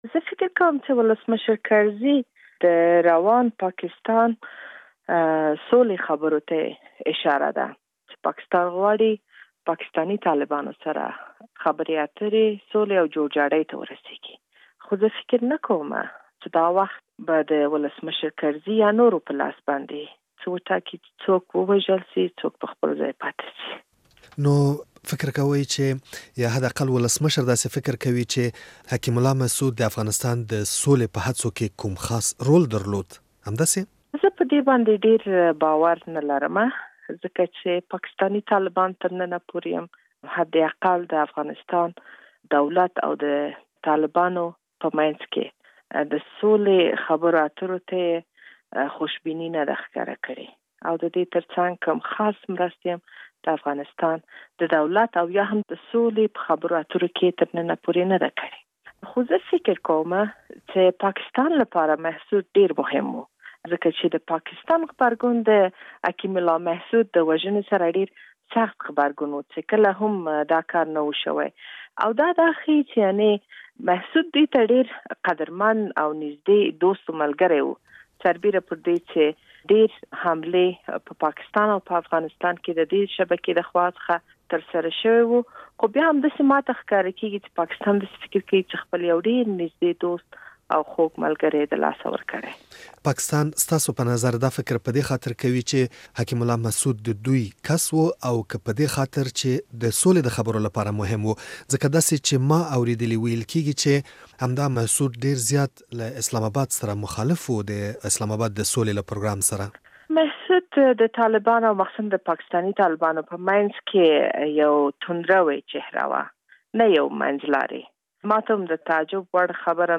له شکريې بارکزۍ سره مرکه